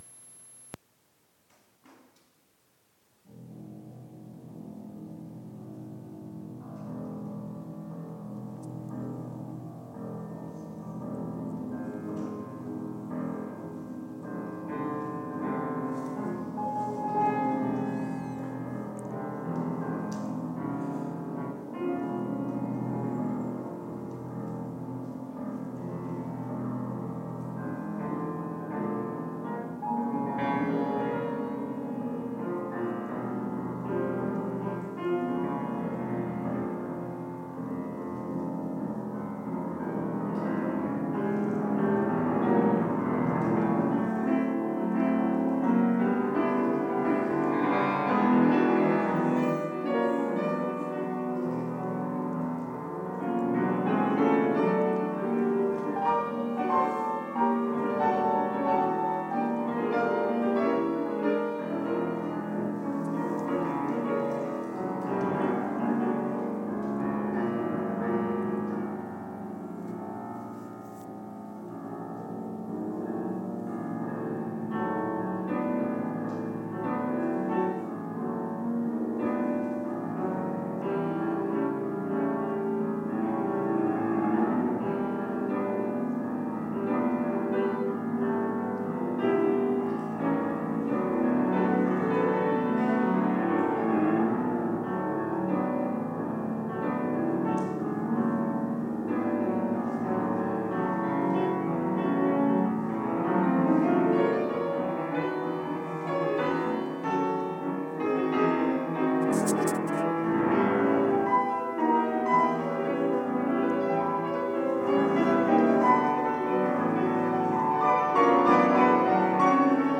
klavierduett 5